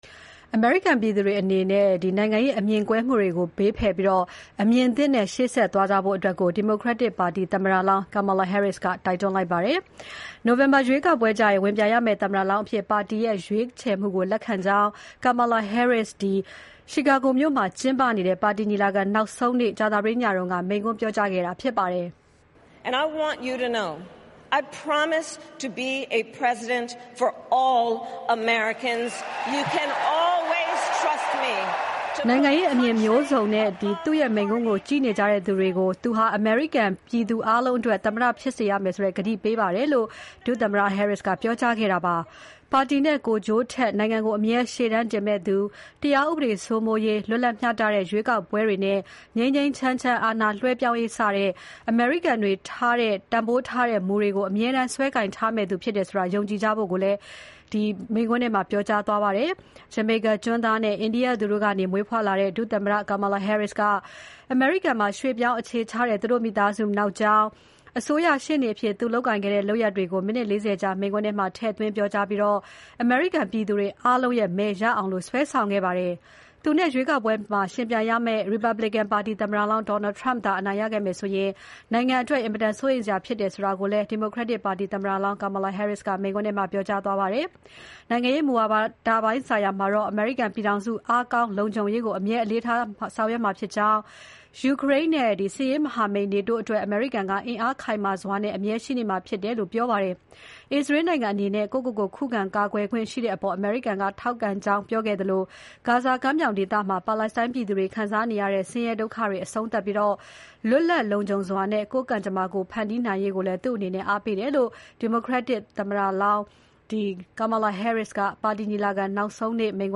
နိုဝင်ဘာရွေးကောက်ပွဲမှာ ဝင်ပြိုင်ရမယ့်သမ္မတလောင်းအဖြစ် ပါတီရဲ့ ရွေးချယ်မှုကို လက်ခံကြောင်း Chicago မြို့မှာကျင်းပတဲ့ ပါတီညီလာခံနောက်ဆုံးနေ့ ကြာသပတေးနေ့ညက မိန့်ခွန်းပြောခဲ့တာဖြစ်ပါတယ်။